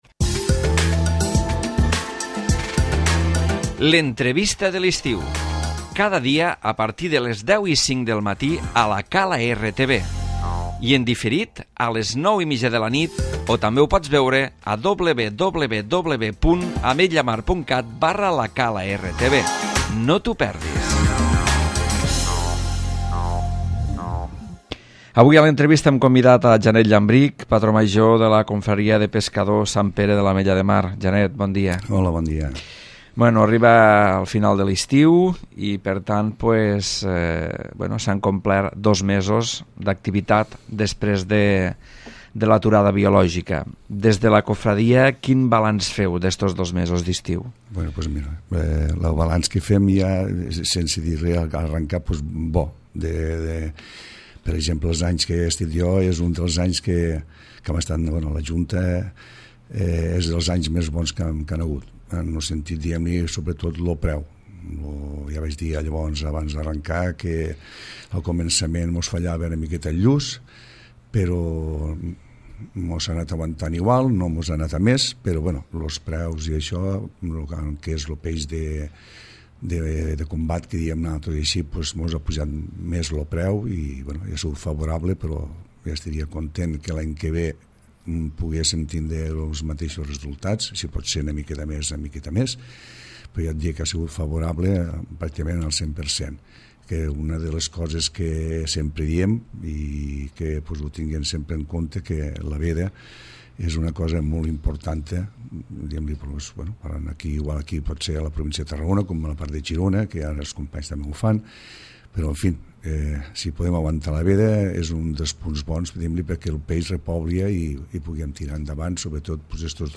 L'Entrevista